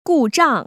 故障[gùzhàng]